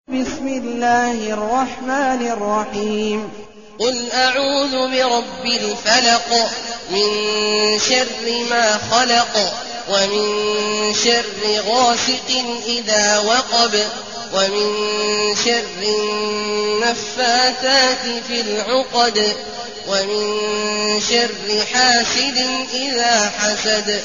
المكان: المسجد الحرام الشيخ: عبد الله عواد الجهني عبد الله عواد الجهني الفلق